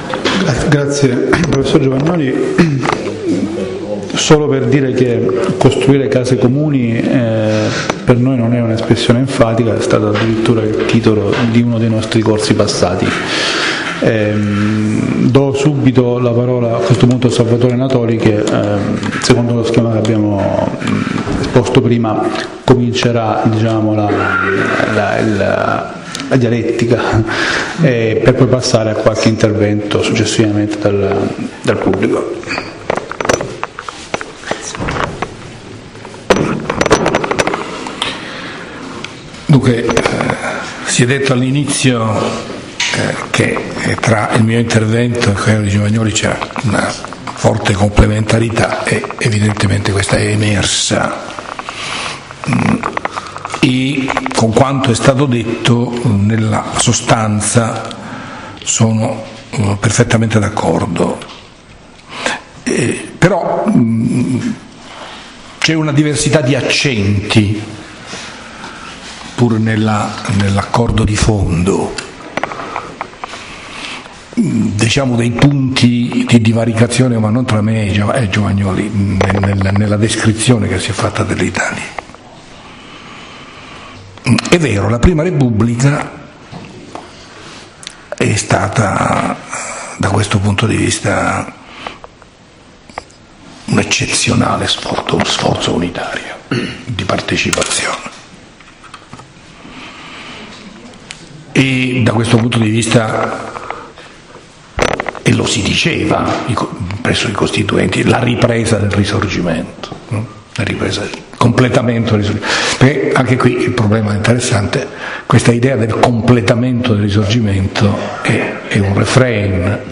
L'Italia: la sua cultura del potere alla prova della contemporaneità è il tema della lezione che apre il corso di formazione 2017-2018 dei Circoli Dossetti